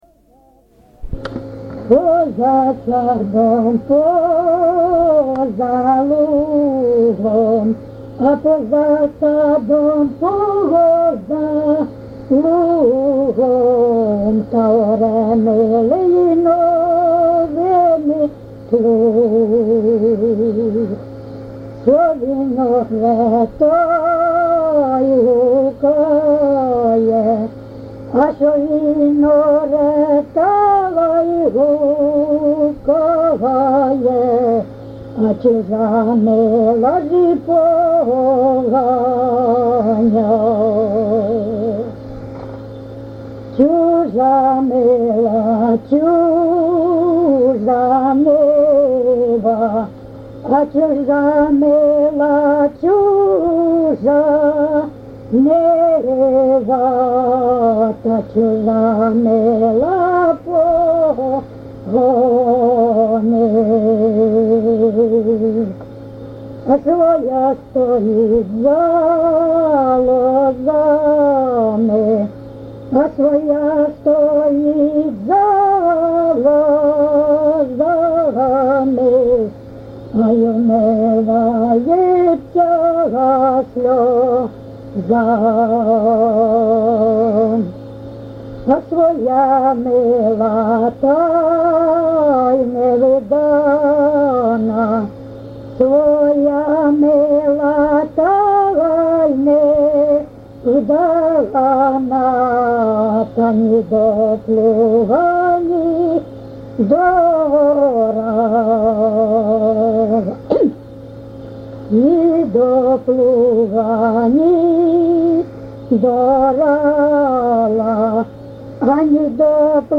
ЖанрПісні з особистого та родинного життя
Місце записус. Коржі, Роменський район, Сумська обл., Україна, Слобожанщина